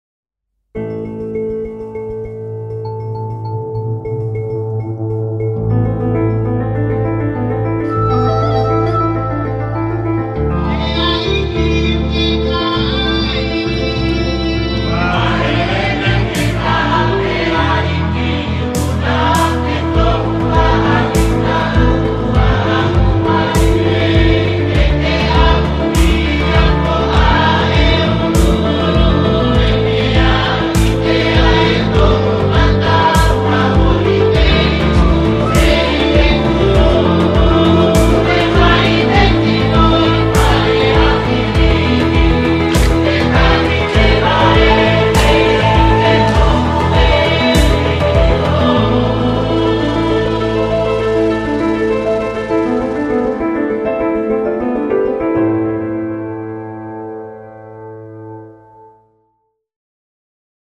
Largo [0-10] suspense - voix - - -